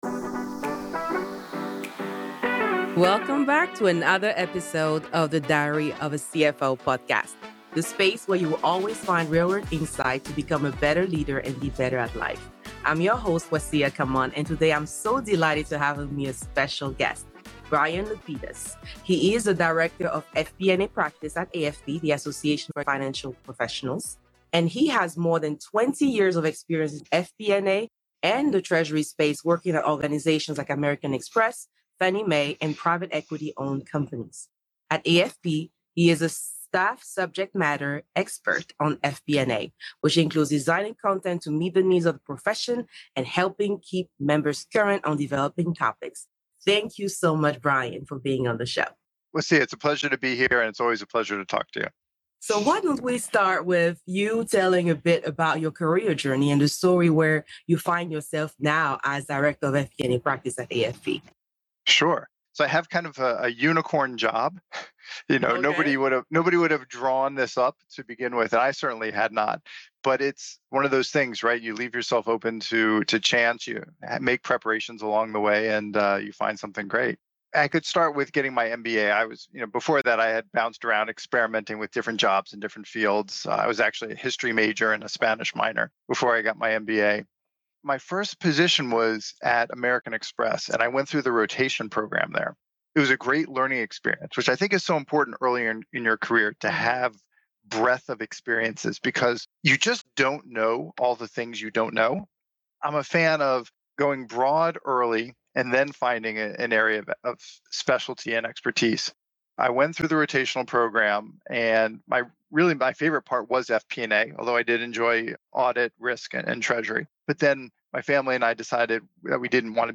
(Guest)